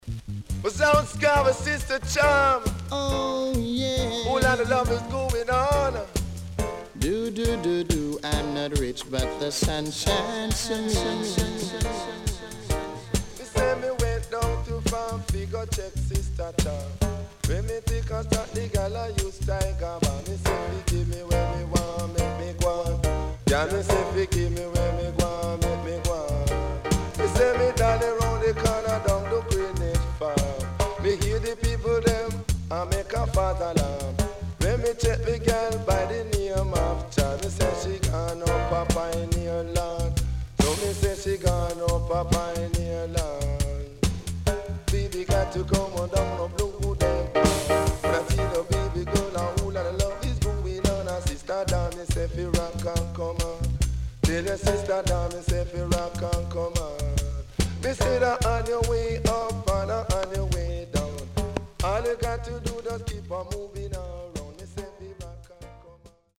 HOME > REGGAE / ROOTS  >  70’s DEEJAY
CONDITION SIDE A:VG+〜EX-
SIDE A:かるいヒスノイズ入りますが良好です。